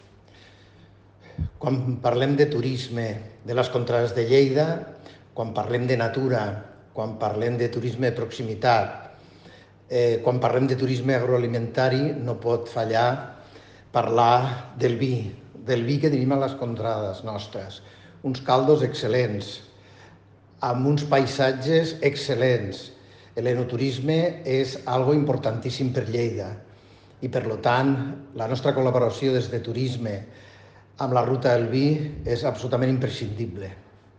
Tall de veu del tinent d'alcalde Paco Cerdà sobre la renovació de la col·laboració de Turisme de Lleida amb la Ruta del Vi de Lleida